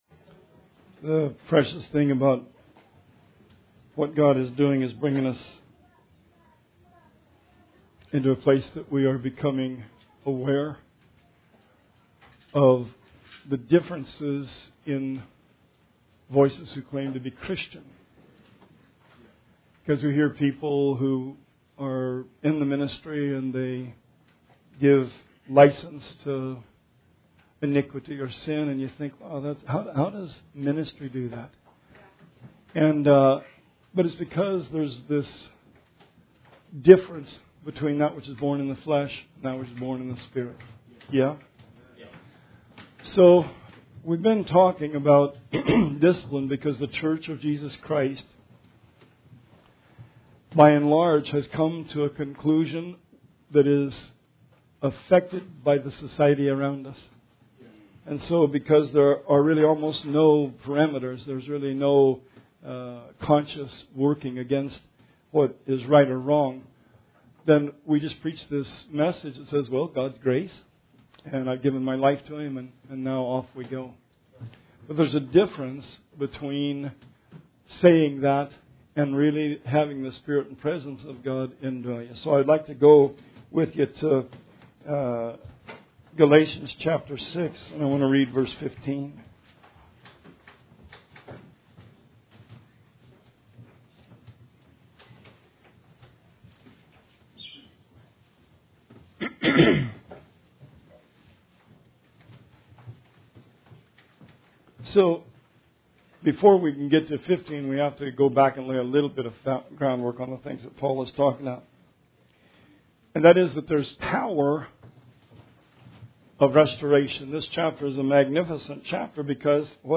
Sermon 8/4/19